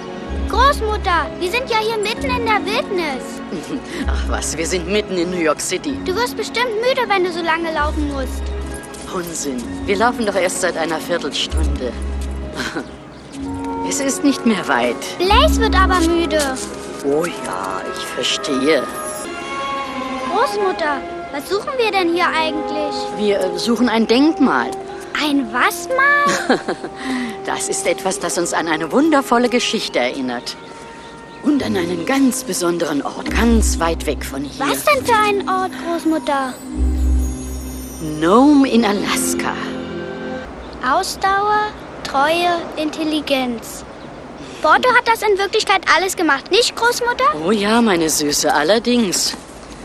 Großmutter Rosy & Enkelin